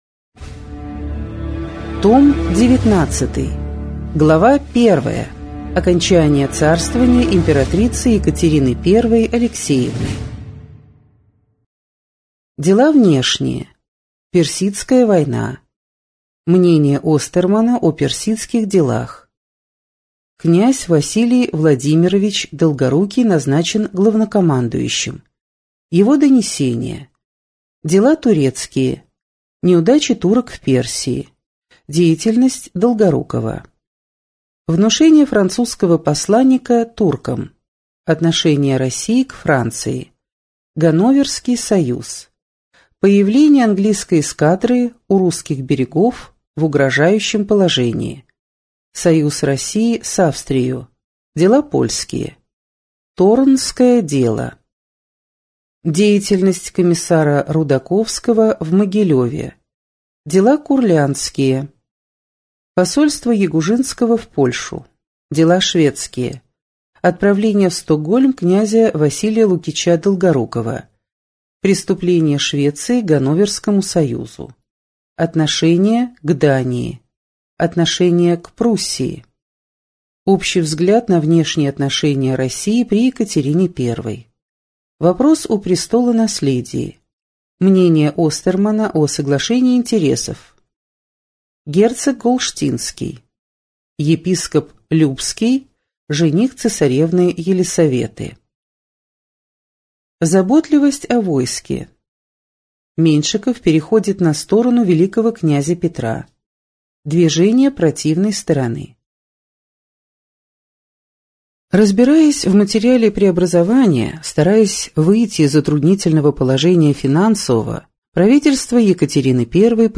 Аудиокнига История России с древнейших времен. Том 19.